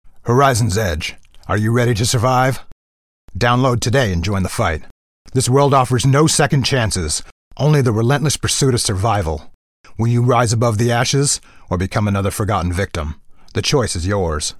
Male
Radio Commercials
Words that describe my voice are Warm, natural, enaging.